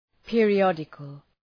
Προφορά
{,pıərı’ɒdıkəl}